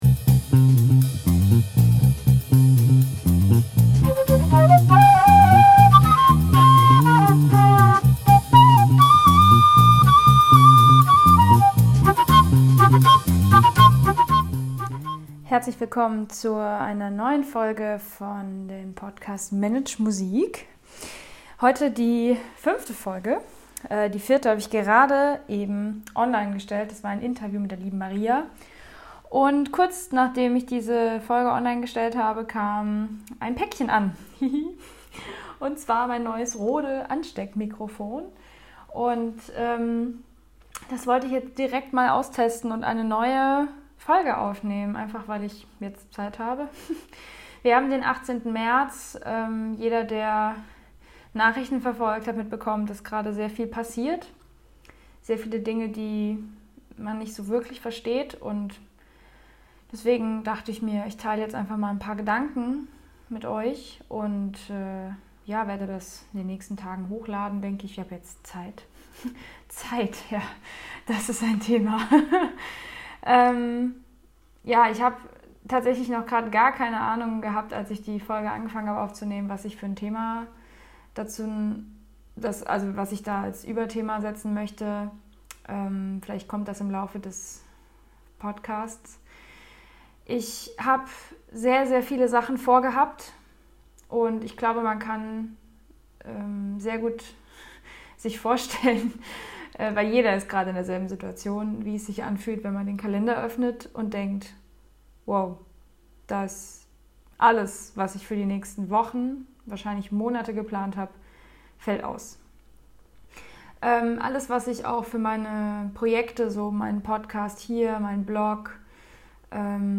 Meine Gedanken und Ideen zum Thema Coronakrise. Ursprünglich wollte ich nur das neue Mikro testen, aber es wurde eine Folge daraus, die dem ein oder anderen vielleicht helfen kann.